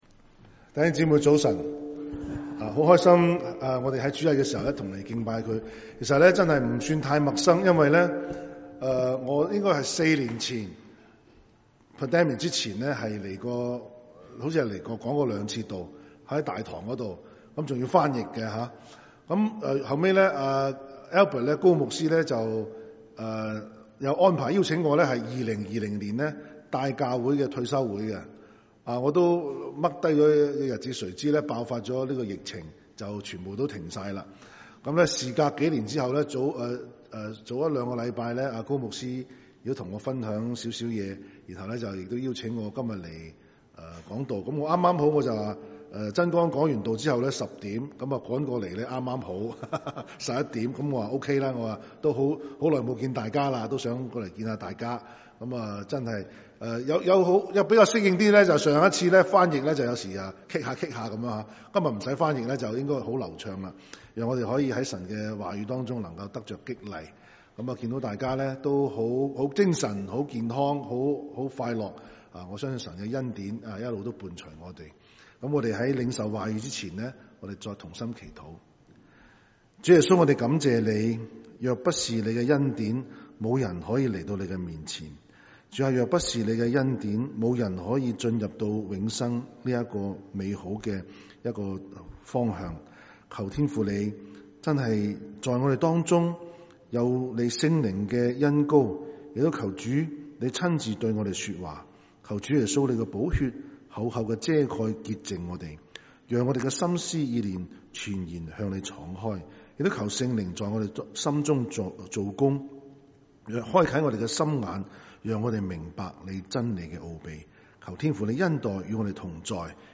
7/9/2023 粵語崇拜: 「活出天國公民的特質」